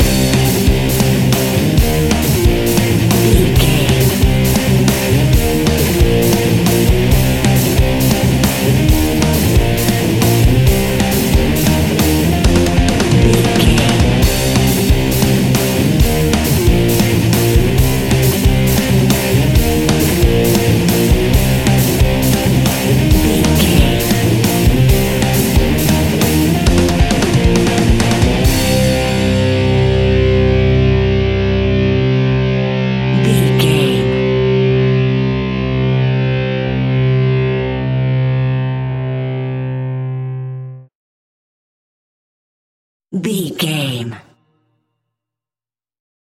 Aeolian/Minor
hard rock
blues rock
distortion
Rock Bass
heavy drums
distorted guitars
hammond organ